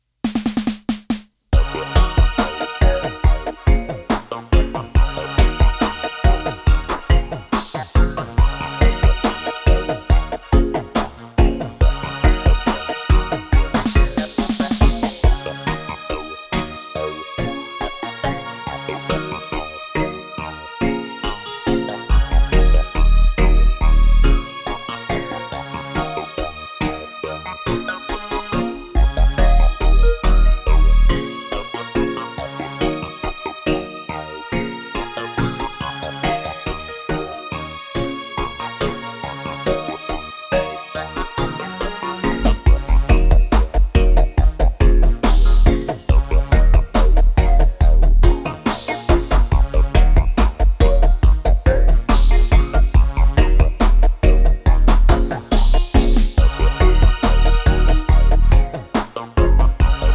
instrumentals
produced and mixed solely on analogue gear.
Both tracks are full of heavy and deep vibrations!